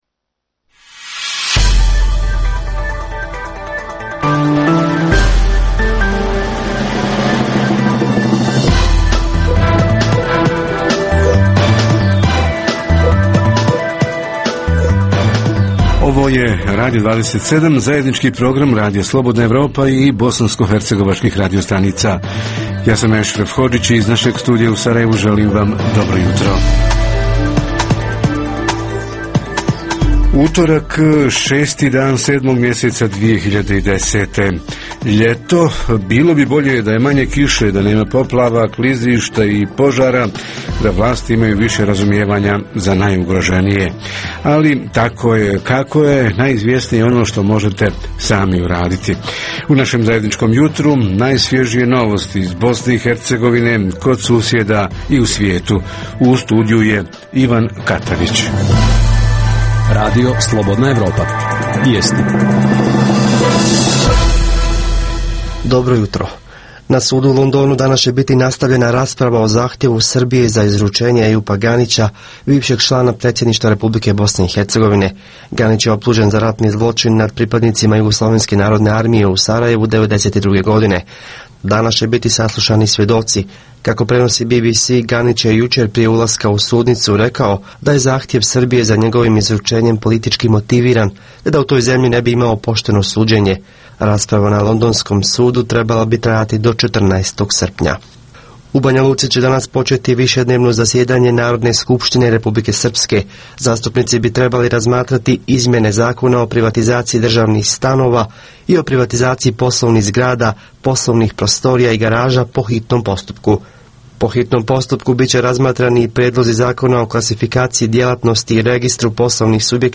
Vijesti emitujemo na početku, na sredini i pred kraj programa. Uz ugodnu muziku, Jutarnji program će „prozvati“ reportere da se jave iz mjesta s aktuelnim događajima.